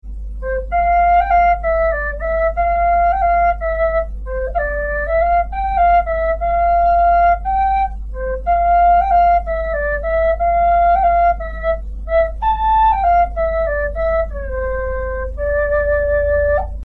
Вистл (C, акация)
Вистл (C, акация) Тональность: C
Вистл из пропитанной древесины акации.